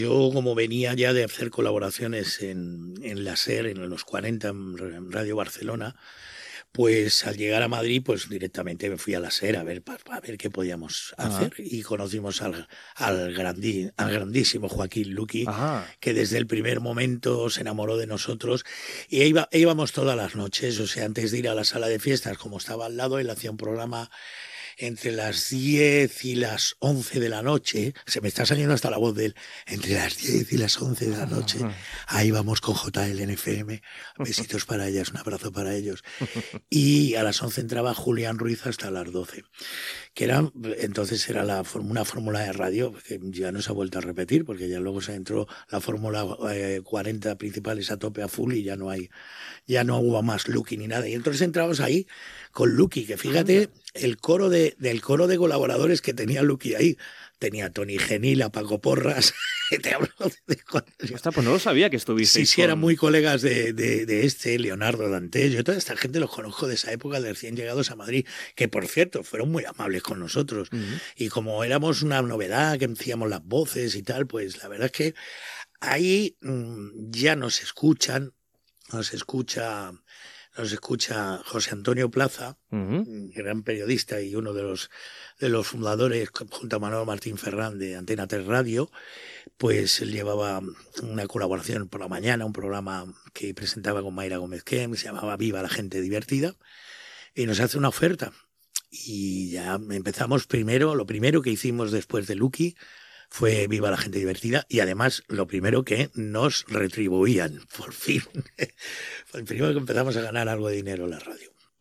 Fragments d'una entrevista a l'humorista Juan Muñoz (Cruz y Raya), amb els seus records de la ràdio barcelonina.
Entreteniment